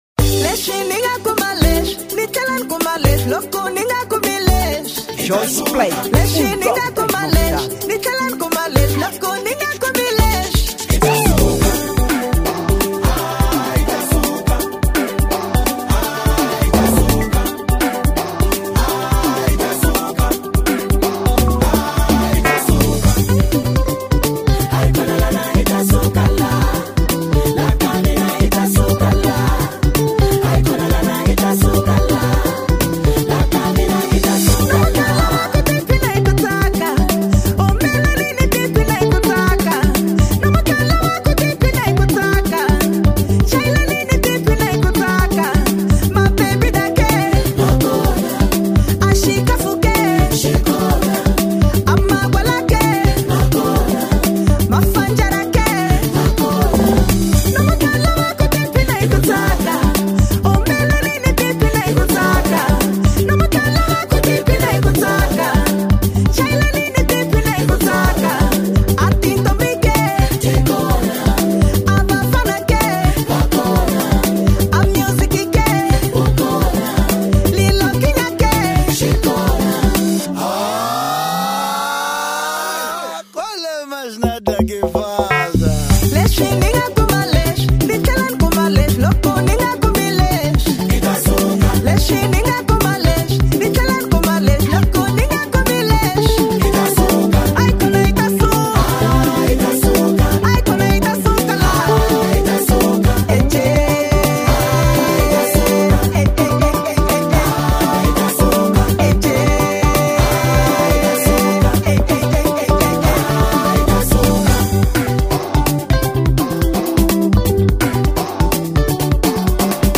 Género: Afro Beat